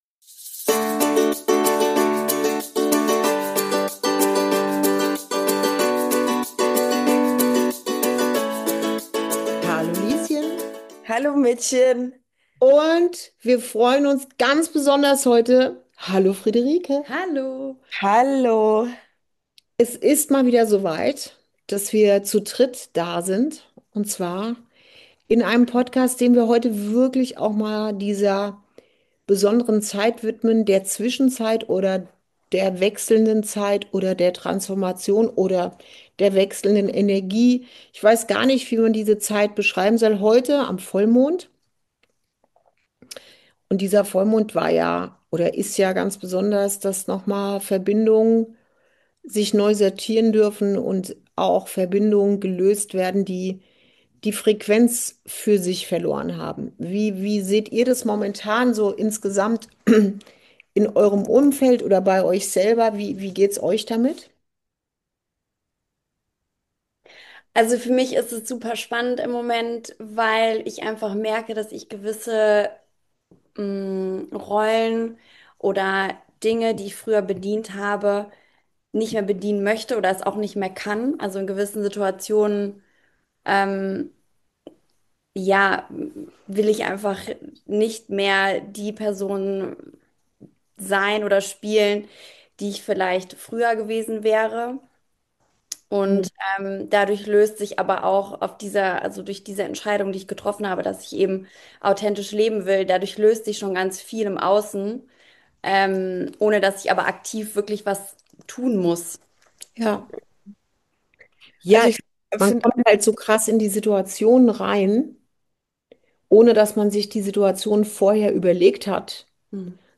059: Wenn du nicht mehr in alte Rollen passt ~ Inside Out - Ein Gespräch zwischen Mutter und Tochter Podcast